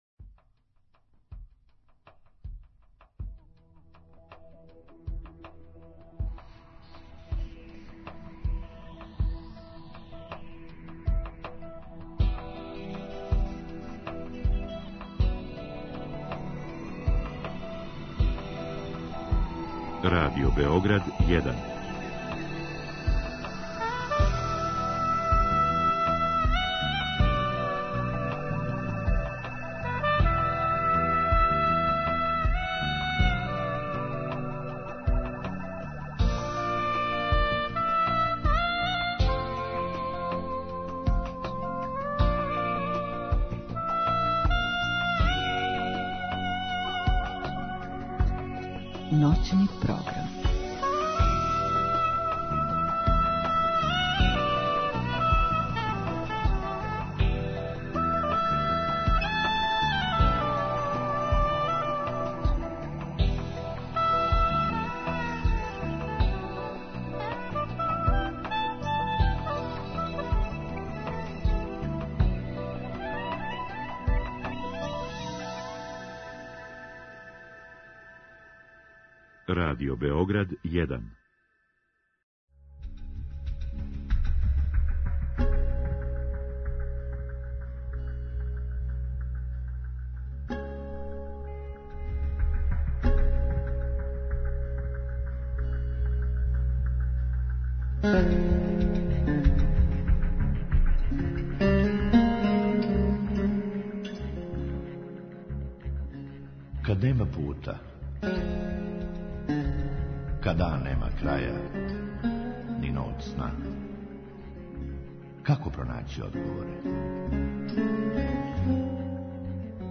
У другом сату слушаоци у директном програму или путем Инстаграм странице емисије могу поставити питање гошћи у вези са темом.